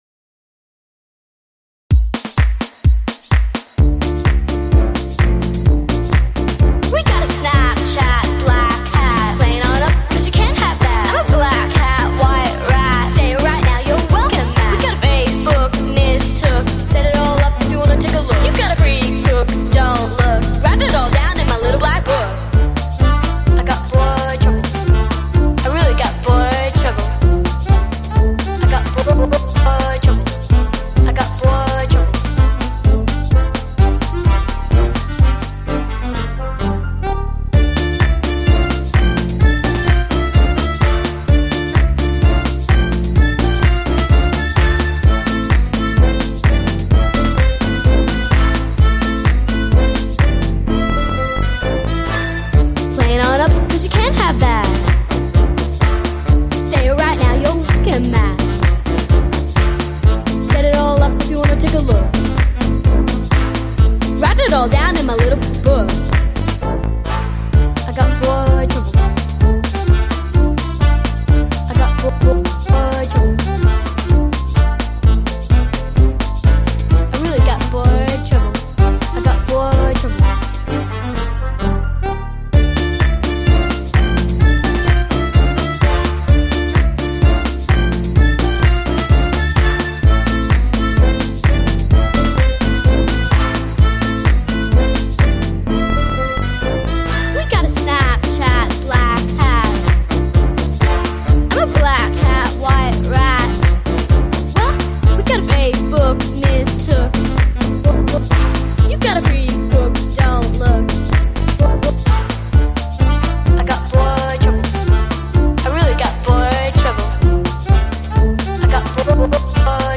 Tag: 电子翼 铜管 骨头 小号 摇摆 低音